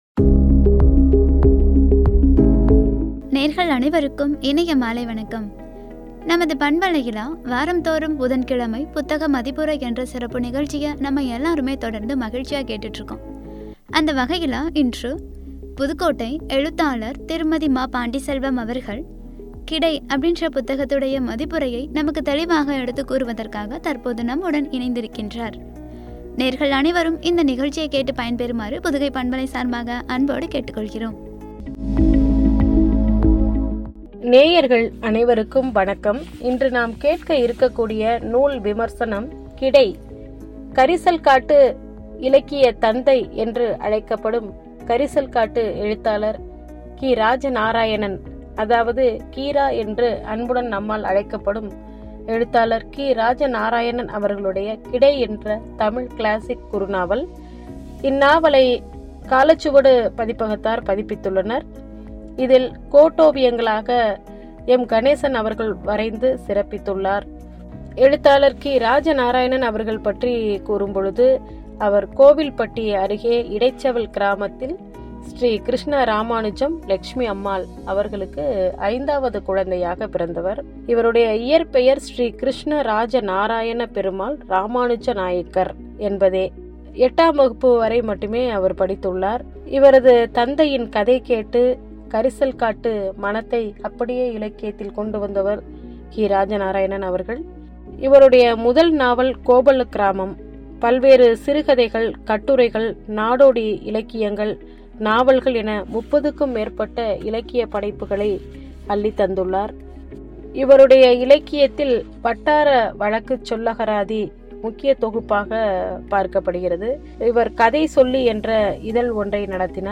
“கிடை”(புத்தக மதிப்புரை) என்ற தலைப்பில் வழங்கிய உரை.